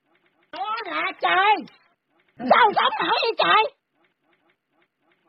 Ơi là trời, sao sống nổi vậy trời… meme sound effect
Thể loại: Câu nói Viral Việt Nam
oi-la-troi-sao-song-noi-vay-troi-meme-sound-effect-www_tiengdong_com.mp3